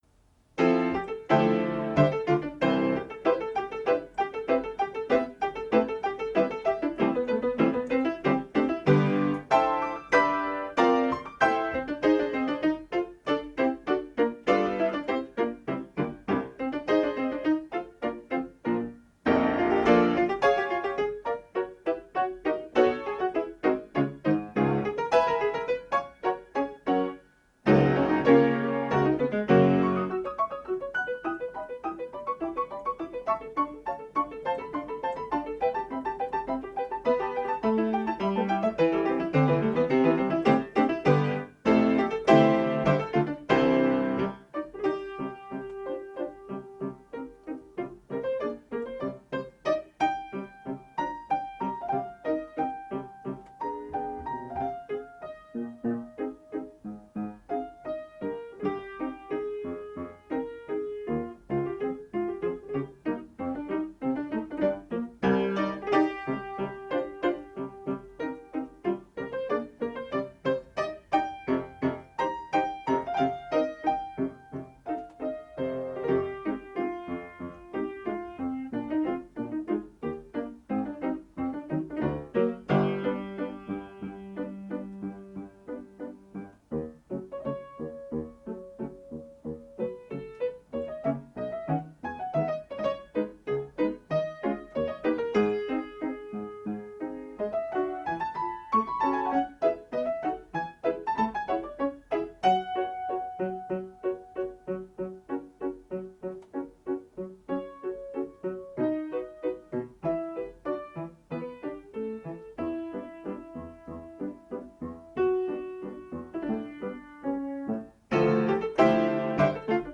리고동은 충분히 활기차게 연주하는 C 장조 곡이다. 박자의 삼부 형식으로, 중간 부분은 속도를 늦추고 가단조로 조바꿈한다.